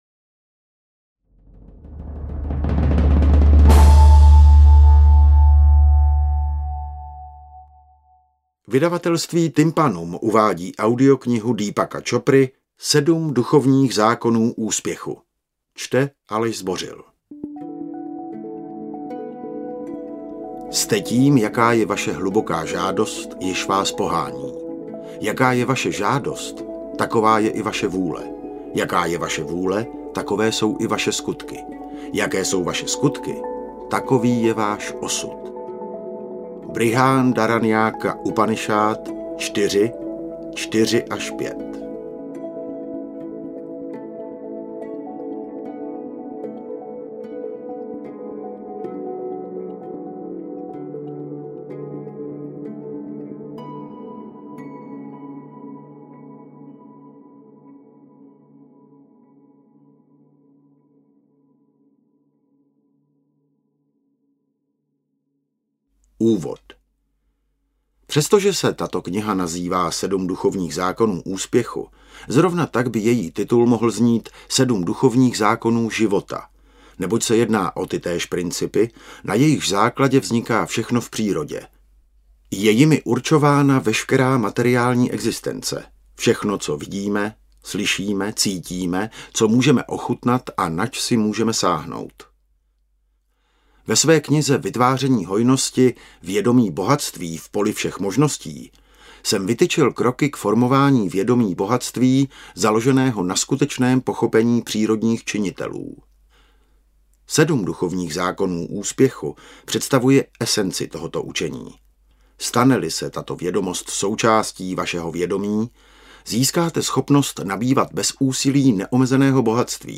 Audiokniha Sedm duchovních zákonů úspěchu vám umožní dosáhnout mistrovské úrovně životního konání. Světový odborník na poli vědomí Deepak Chopra zkoncentroval esenci svého učení do sedmi prostých, ale mocných principů, které lze snadno uplatnit ve všech oblastech života.